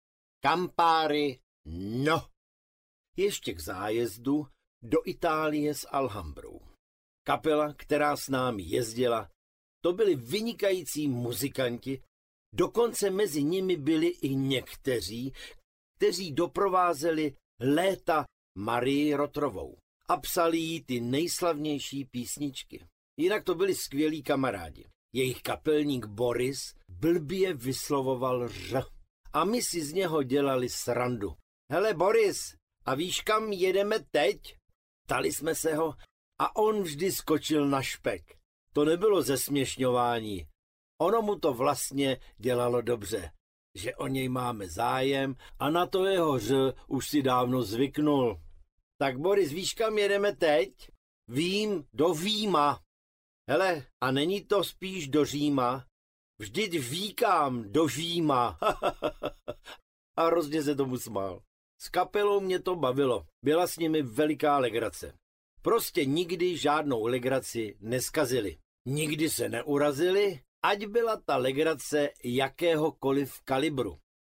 Cestopisy audiokniha